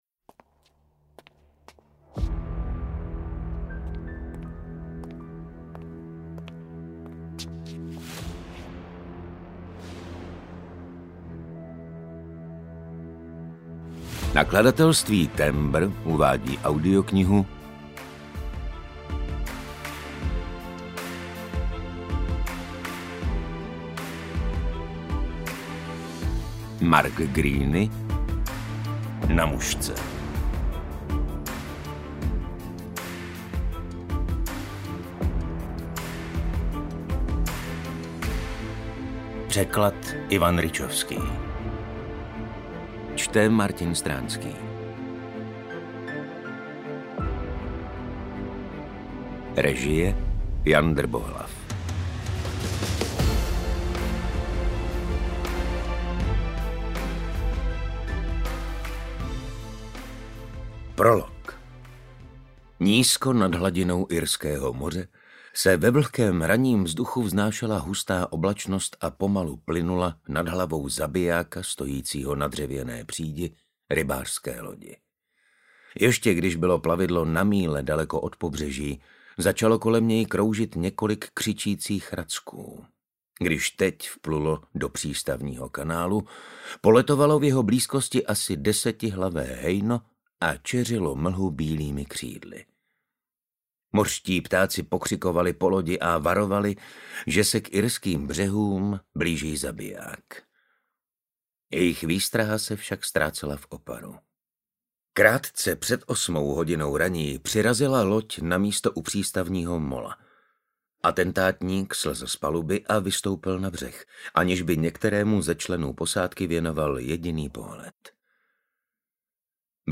UKÁZKA Z KNIHY
audiokniha_na_musce_ukazka.mp3